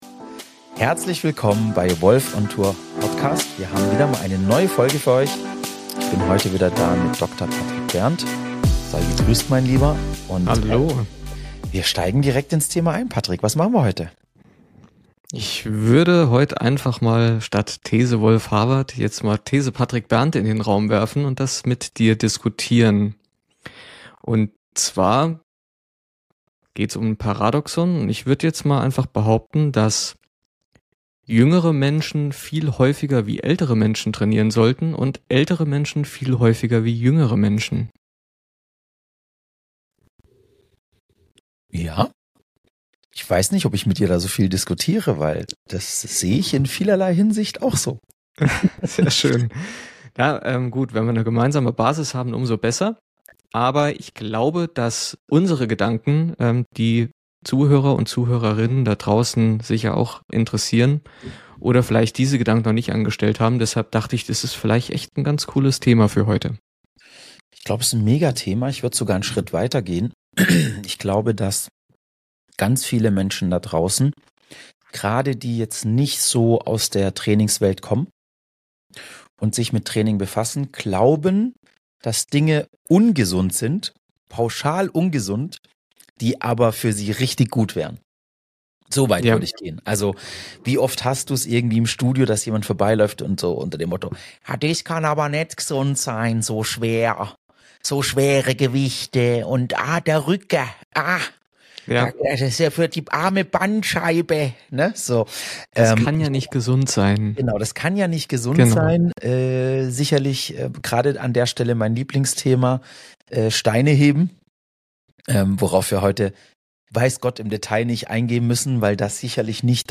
Ein tiefes Gespräch über Körperwahrnehmung, Regeneration und mentale Stärke im Sport.